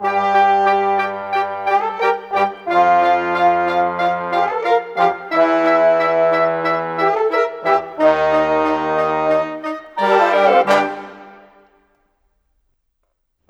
Rock-Pop 07 Brass _ Winds 01.wav